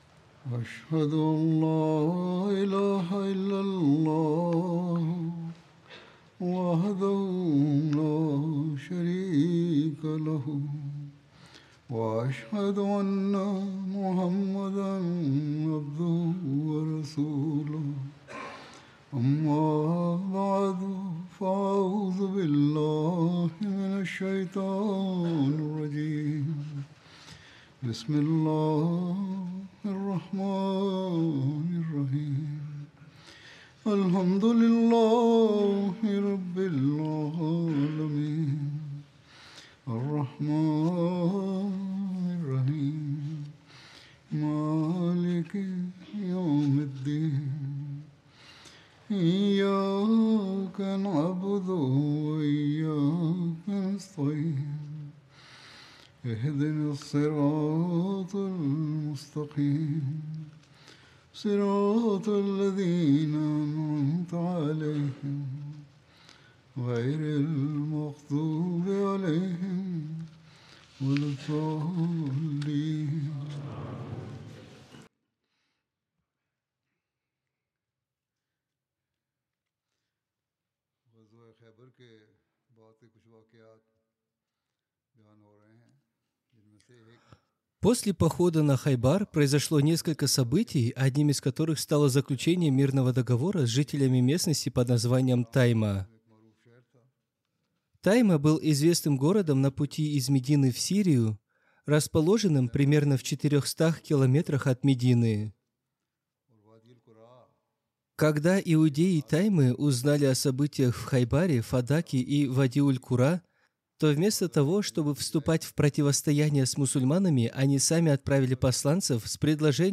Russian translation of Friday Sermon delivered by Khalifa-tul-Masih on August 2nd, 2024 (audio)